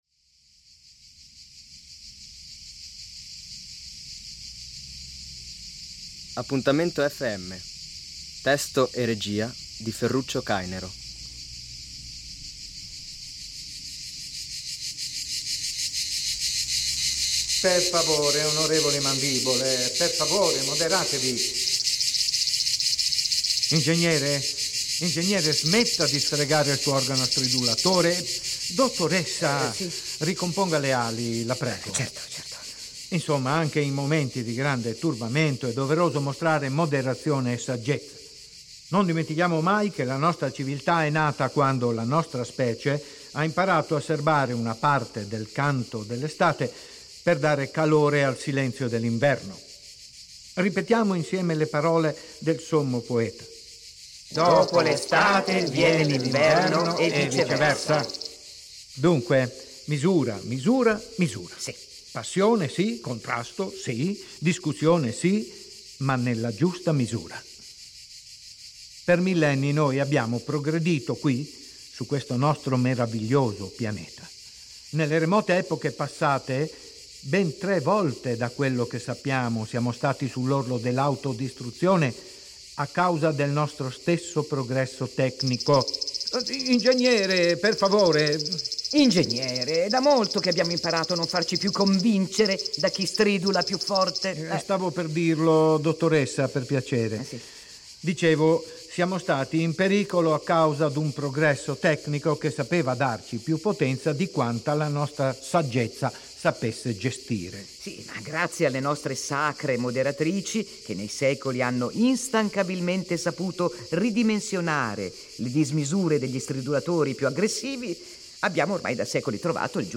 Un’originale radiofonico scritto e diretto da Ferruccio Cainero
“Appuntamento FM” - Radiotragedia comica a due voci umane e una artificiale